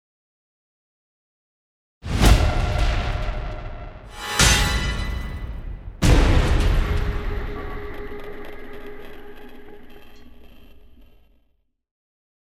Звуки кошмара
8 Жуткий резкий звук перехода